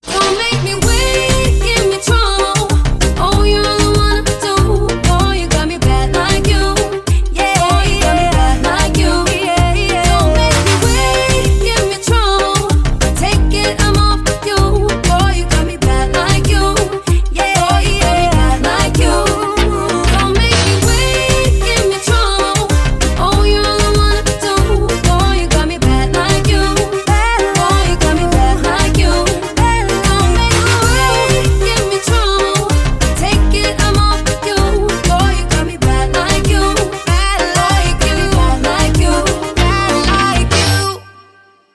dance
vocal